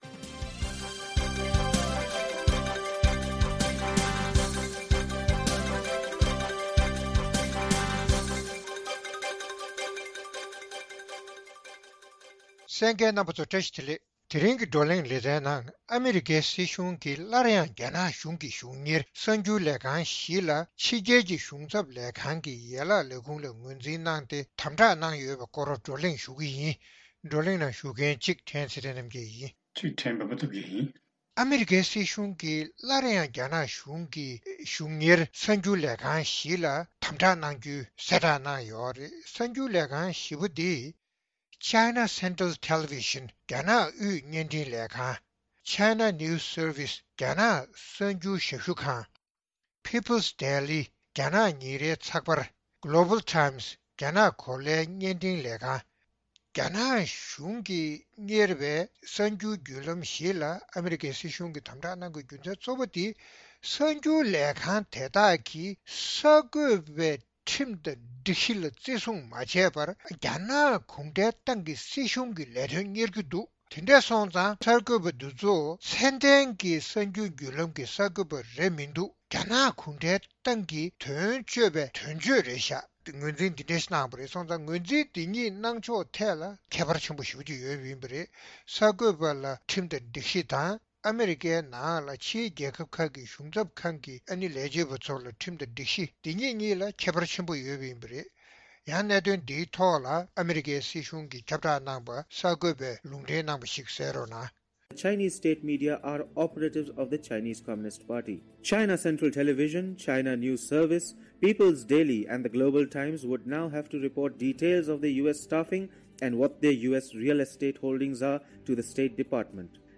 བགྲོ་གླེང་གནང་བ་གསན་རོགས་གནང་།།